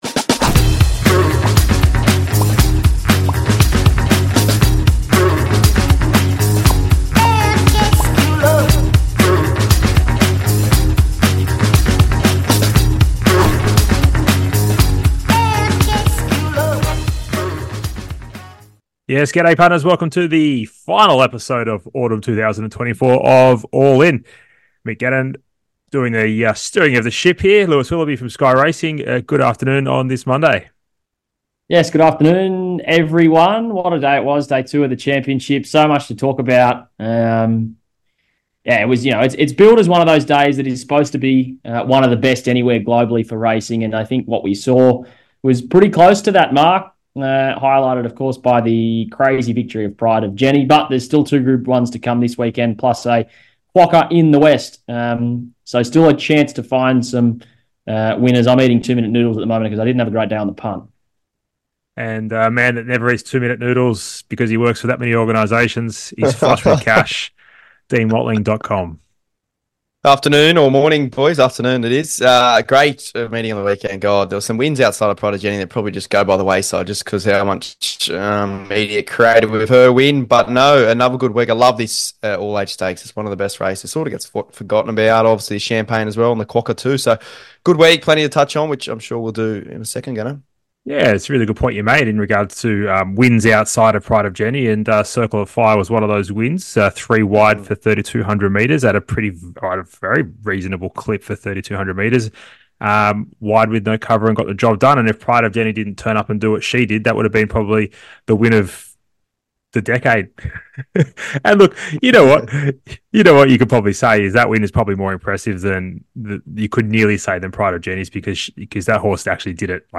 Plenty of laughs, banter and red hot tips to help you have a cracking weekend.